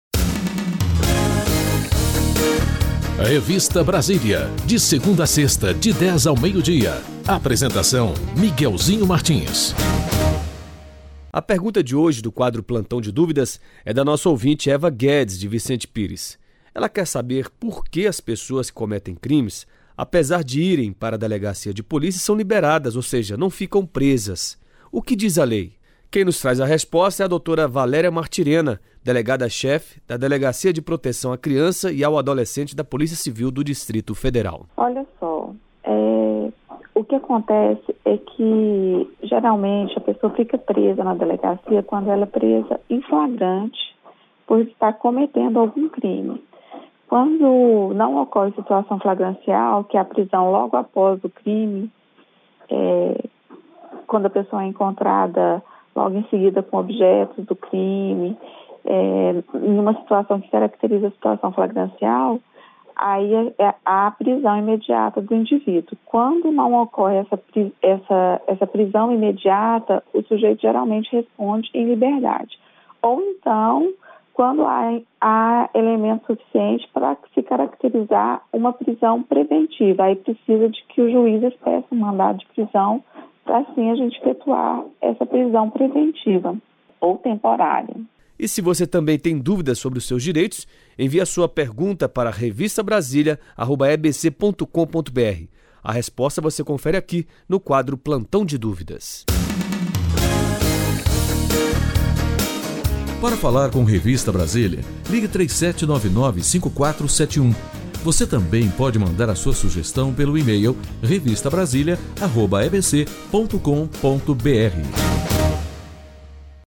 Delegada tira dúvida de ouvinte em programa de rádio
O programa Revista Brasília está no ar, ao vivo, de segunda a sexta, das 10h às 12h. Rádio Nacional de Brasília, AM 980.